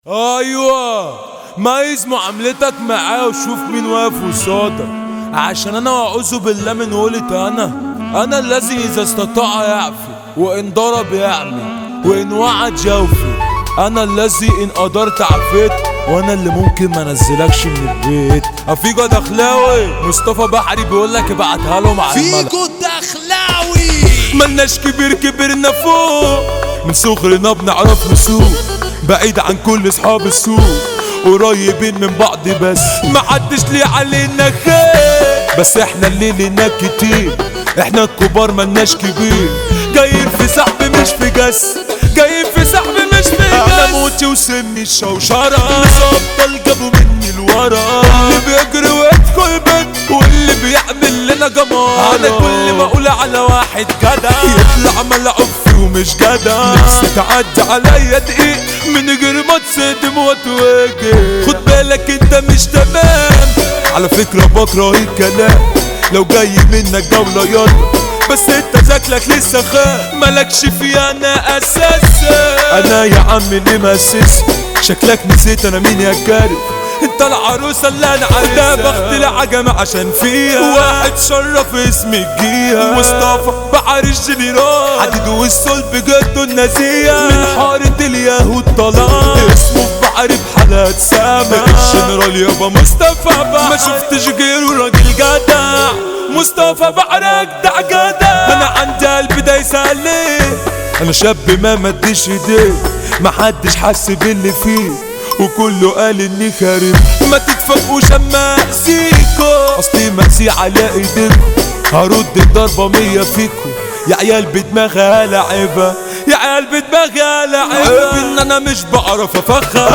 مهرجانات